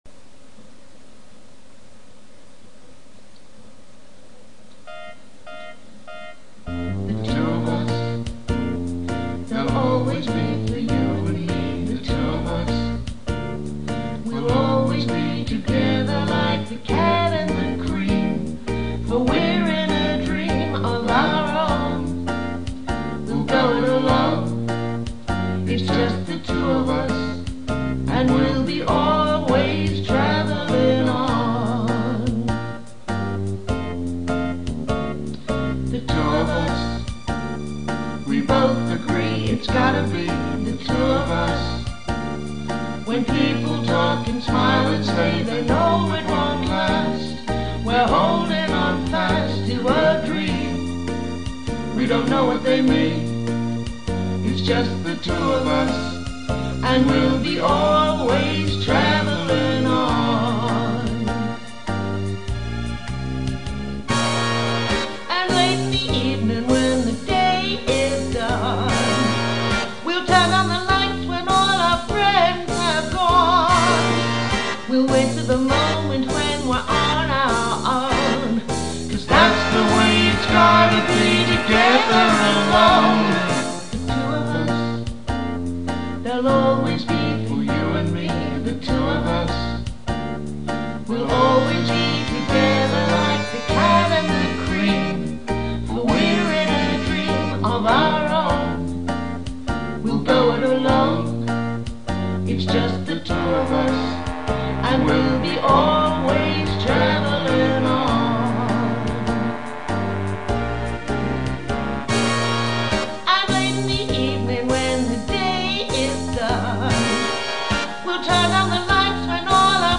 THE TRUE INSTRUMENT SOUNDS AT LAST!
A Very Special Vocal Duet.